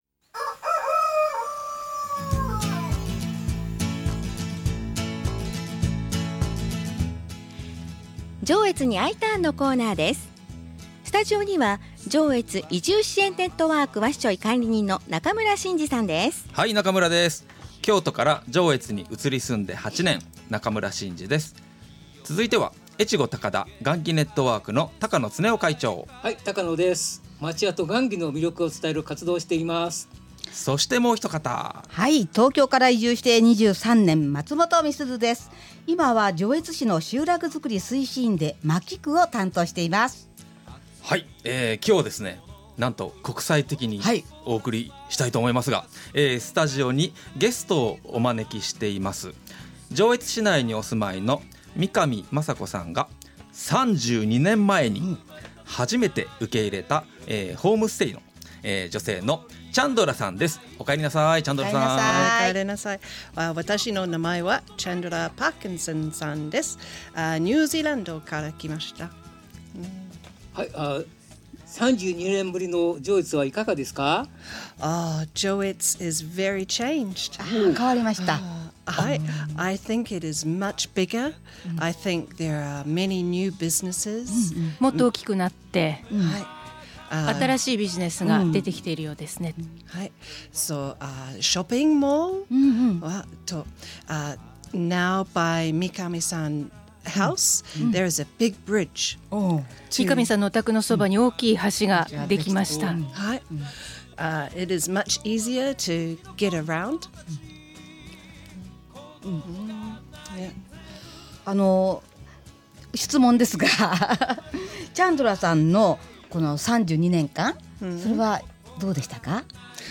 FM-Jのスタジオから市外にお住まいの方に電話をして、直接移住をお誘いするコーナー。
ニュージーランドと上越をくらべ、ニュージーランドの方が良い点は、どんなことでしょう？英語と日本語が入り混じる特別回です。